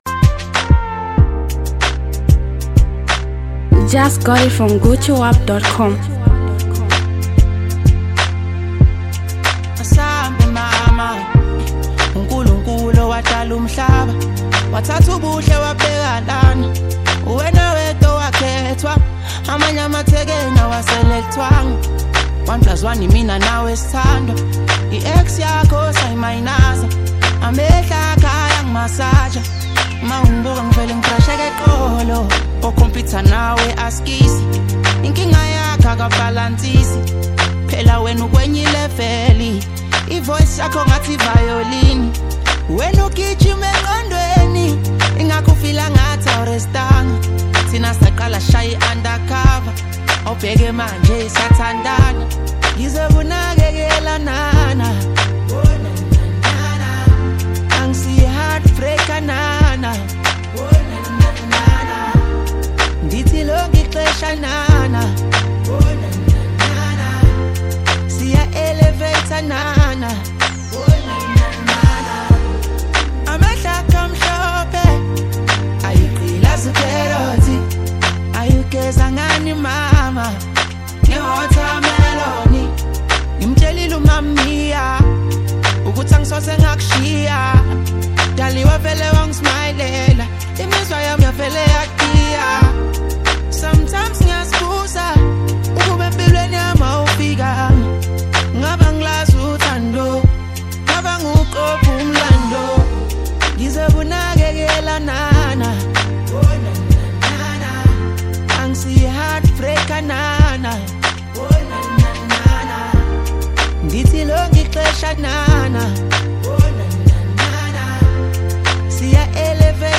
buzzing street anthem